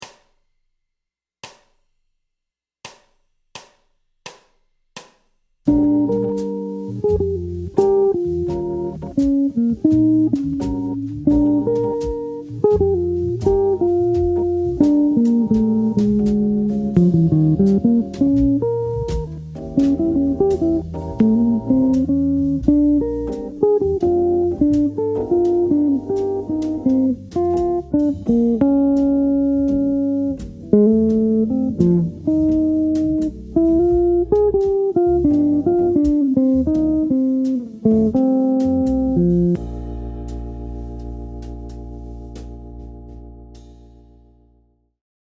Un trait mixolydien avec un triolet sur le temps 3 pour dynamiser la phrase.
La phrase s’appuie sur les notes contenues dans le mode de Bb mixolydien (sans la Quarte ni la Neuvième) Exemple d’emploi du Lick dans un Blues en Bb
Blues-lick-12-1.mp3